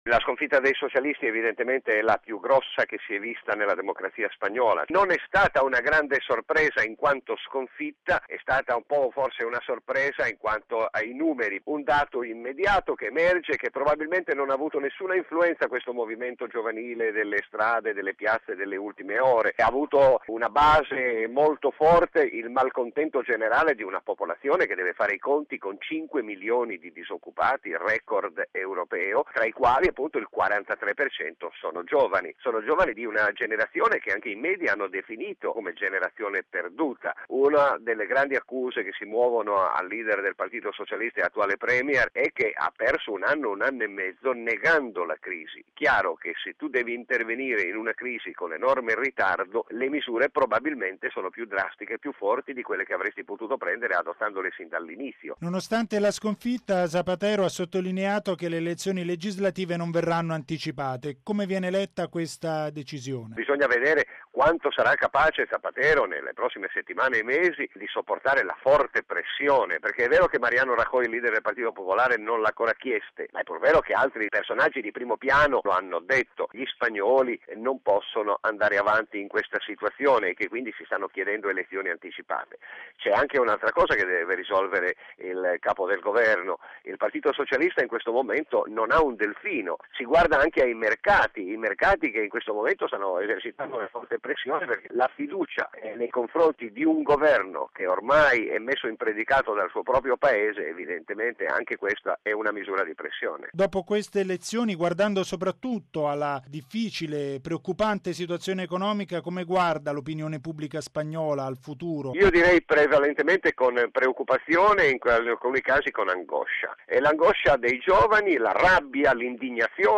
Per una riflessione sul risultato di queste elezioni